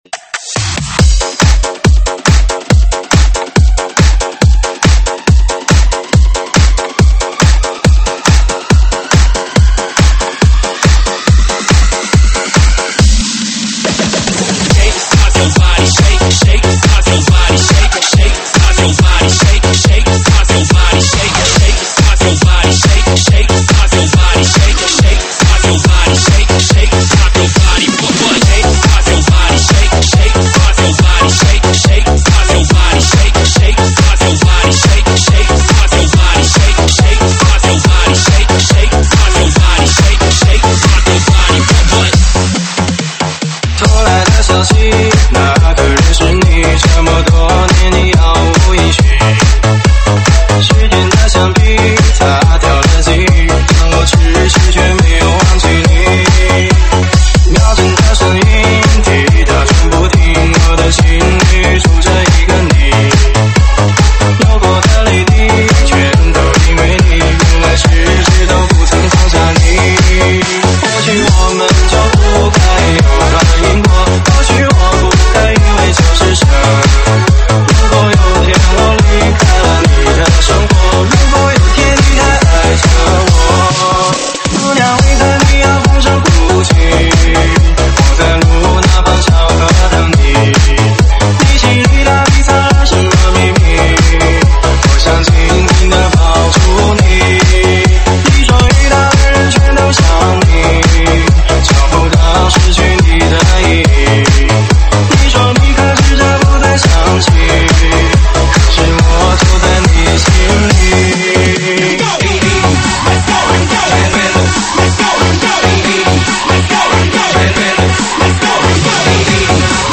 电子Electro